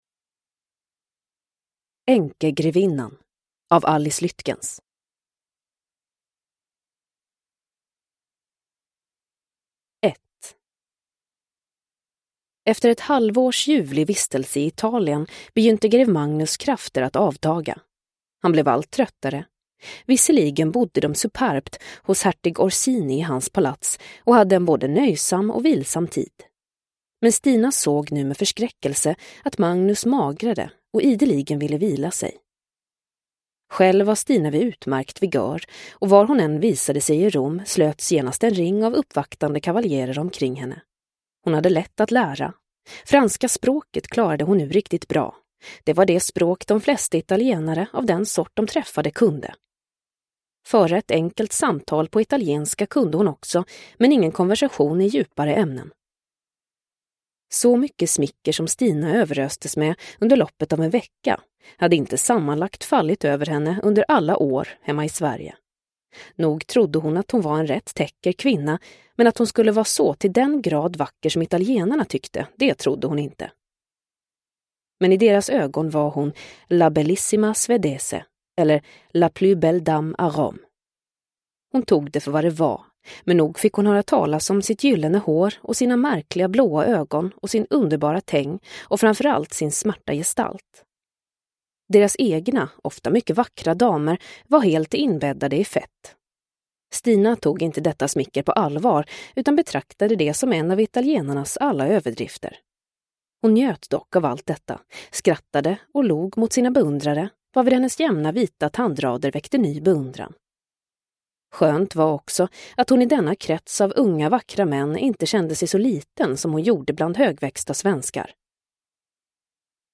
Änkegrevinnan – Ljudbok – Laddas ner